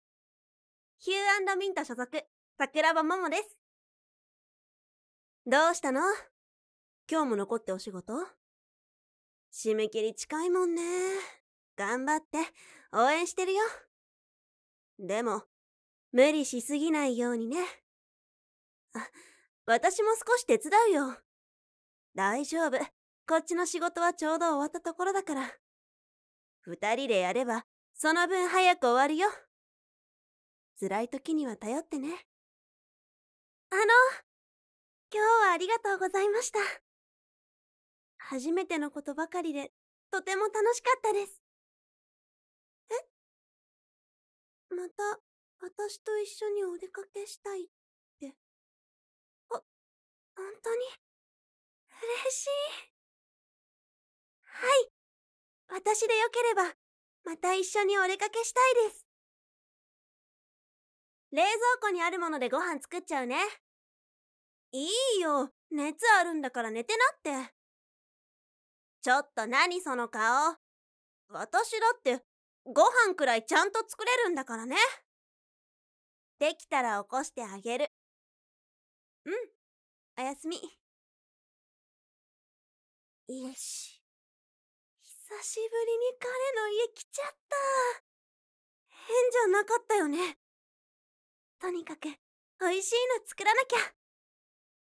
■ボイスサンプル：
方言/外国語：関西弁